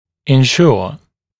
[ɪn’ʃuə] [en-][ин’шуа ] [эн-]обеспечивать, гарантировать